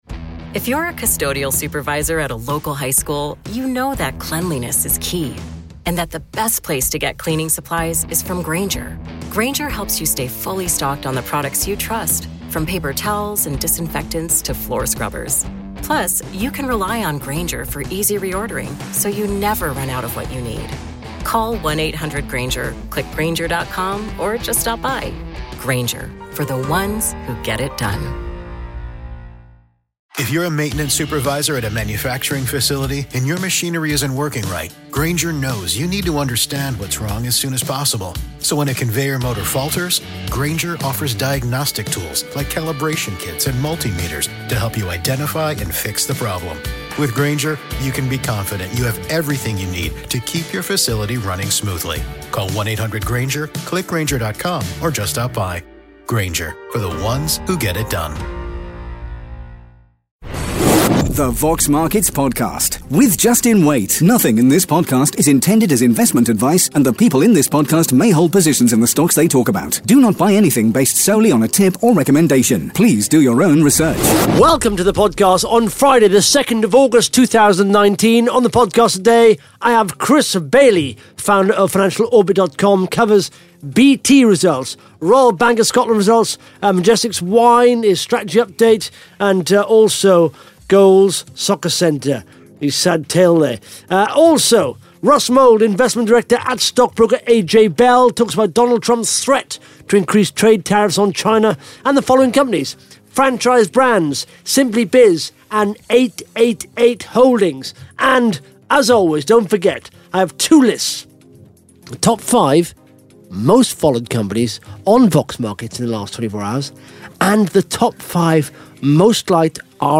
(Interview starts at 18 minutes 12 seconds) Plus the Top 5 Most Followed Companies & the Top 5 Most Liked RNS’s on Vox Markets in the last 24 hours.